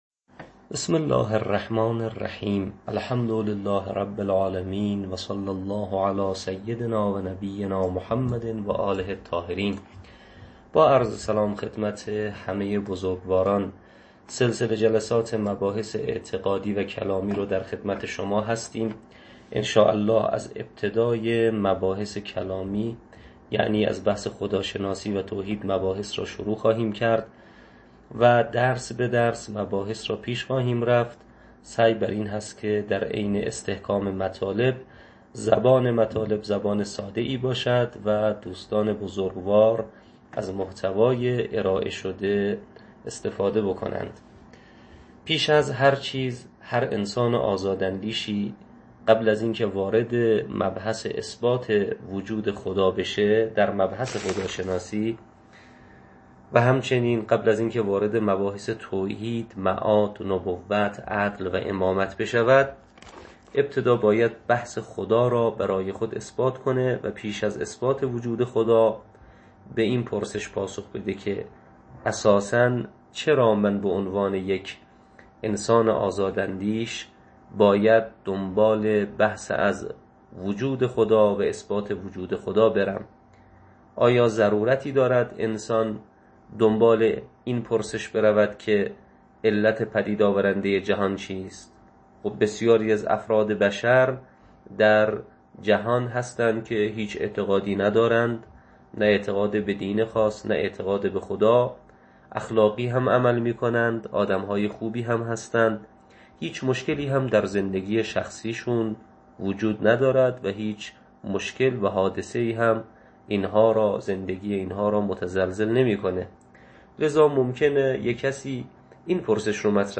تدریس عقاید استدلالی یک